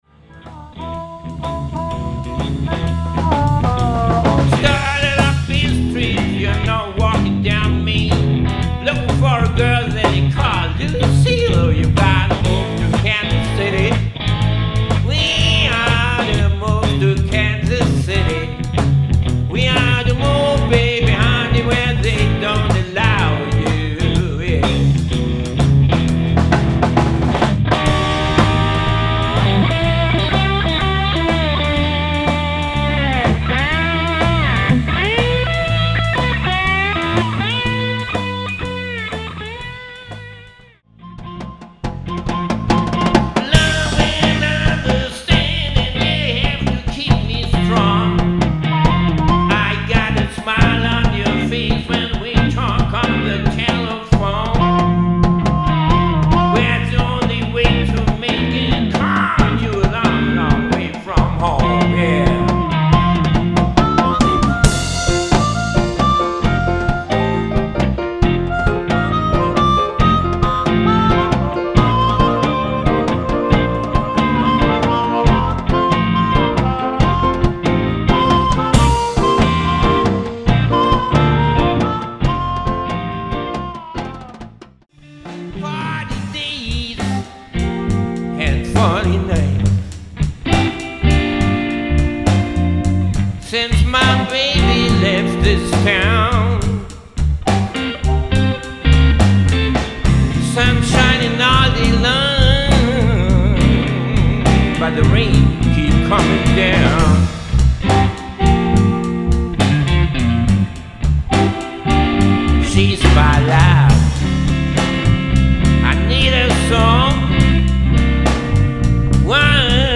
voc, keyb, harm.
git, voc
bass
drums
harmonica